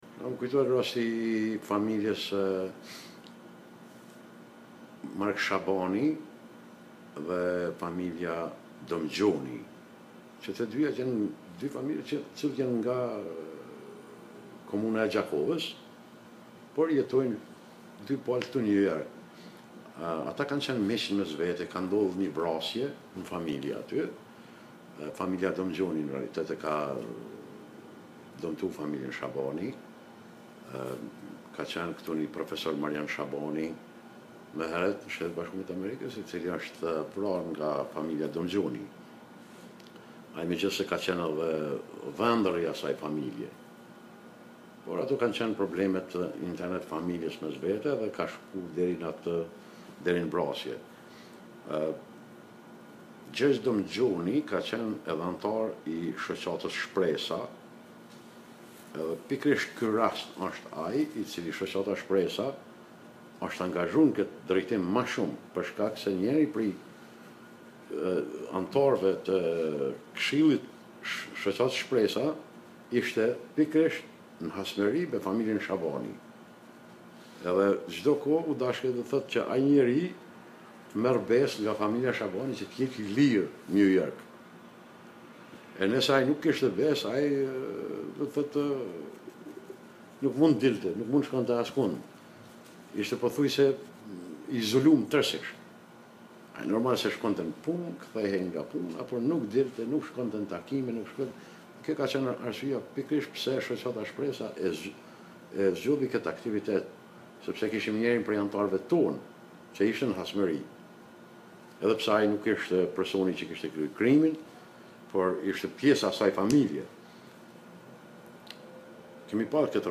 The audio interview section